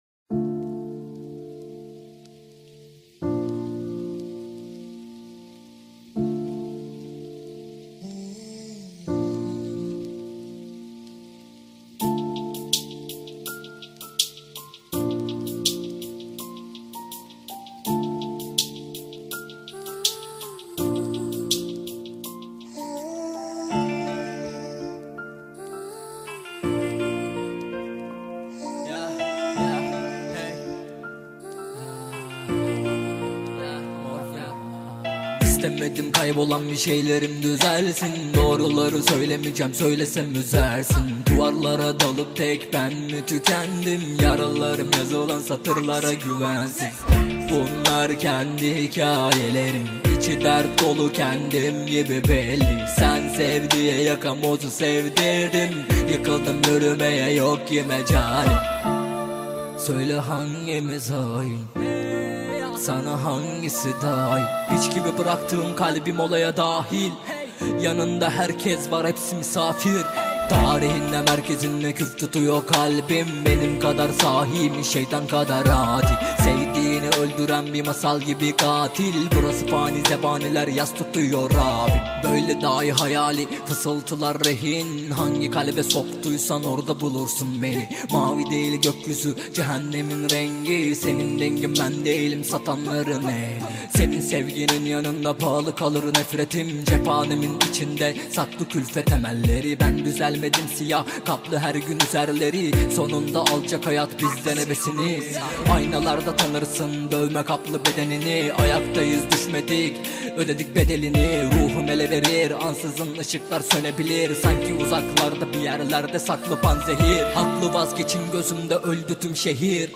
Турецкая песня